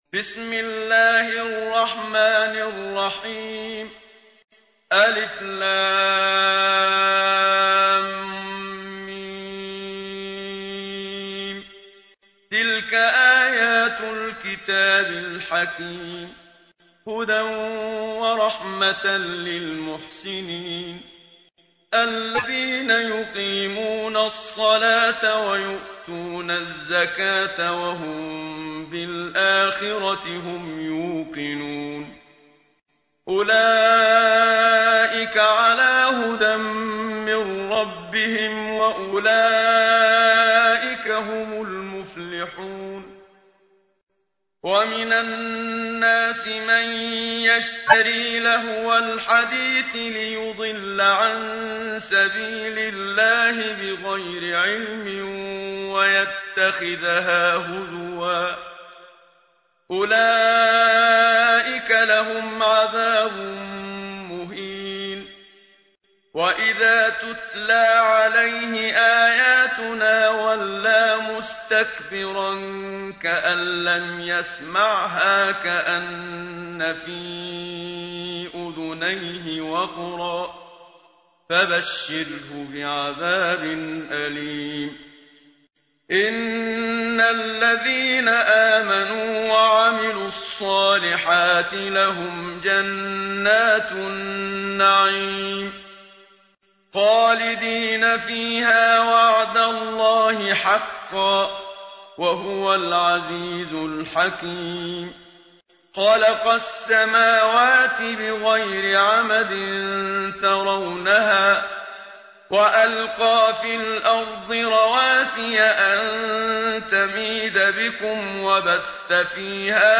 تحميل : 31. سورة لقمان / القارئ محمد صديق المنشاوي / القرآن الكريم / موقع يا حسين